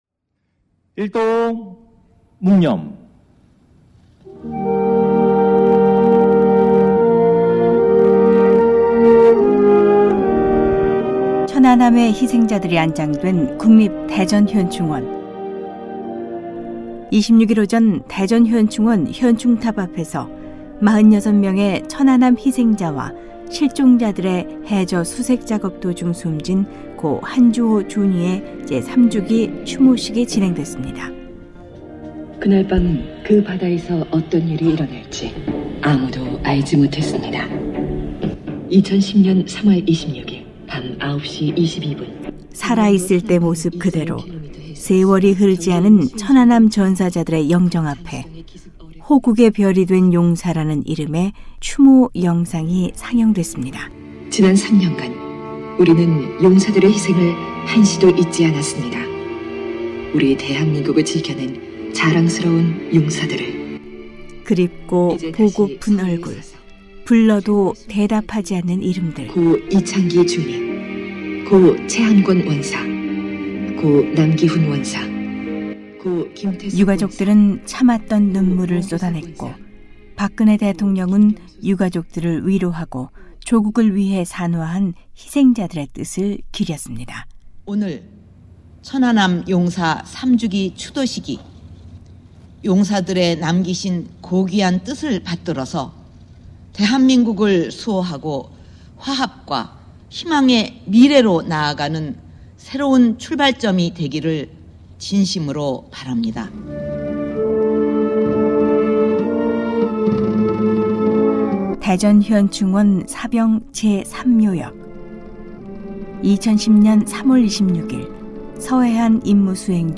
오늘은 지난 26일 천안함 폭침 3주기 맞아 희생자들의 넋을 기리는 참배객들이 이어진 대전현충원의 모습을 전해드립니다. 현충원 현충탑 앞에서는 한국 박근혜대통령이 참석한 추모식이 열렸고, 가족의 영정 앞에 선 유가족과 참배객들은 천안함의 교훈을 잊지 말자고 다짐했습니다.